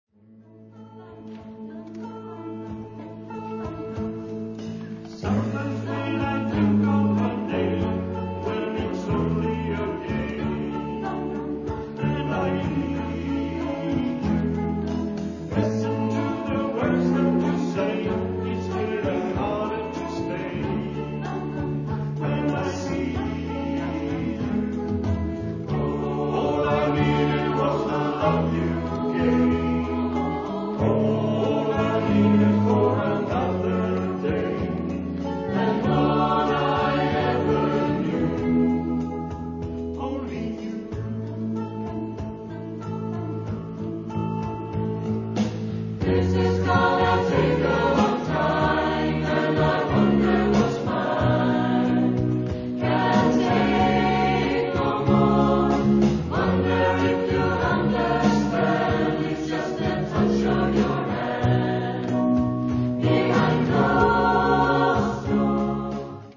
SATB a cappella.
Tenorerna har lead i de två första verserna.
Register: S:C#4-c35, A:G#3-A4, T:C#3-E4, B:F#2-A3
Tema/genre/epok: 80-tal , Kärlek , Pop